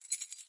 钥匙扣 " 钥匙扣14
描述：录音设备：Sony PCMM10Format：24 bit / 44.1 KHz
Tag: 样品 记录 弗利